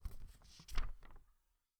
pageturn2.wav